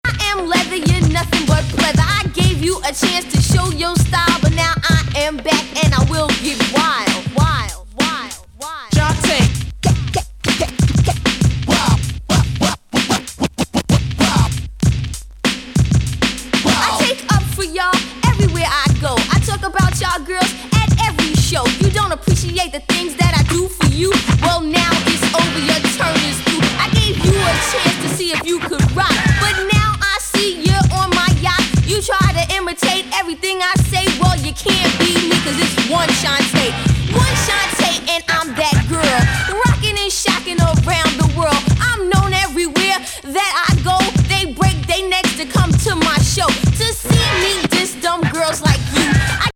US ORG!女OLD SCHOOL CLASSIC!!ズンドコなドラムにスクラッチが切り
男勝りな腰の据わったラップが最高にカッコいい!!